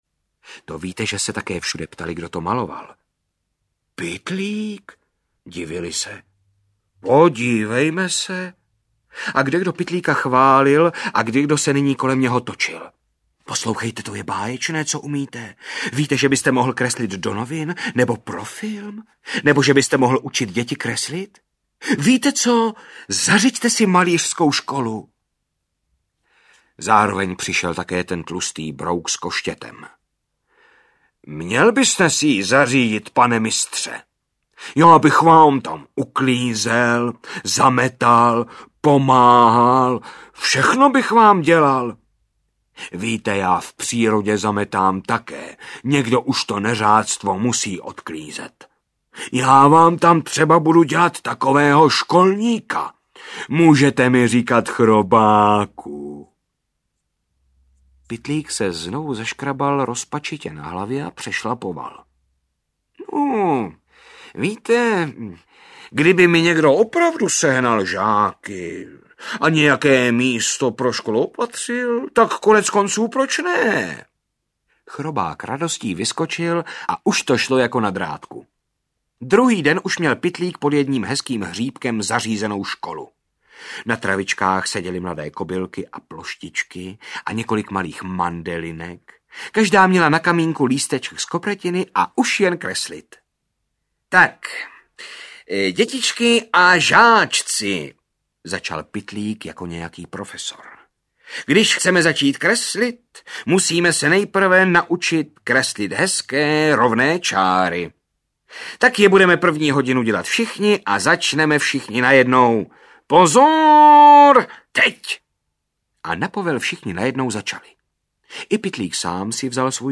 Brouk Pytlík audiokniha
Ukázka z knihy
• InterpretJaromír Meduna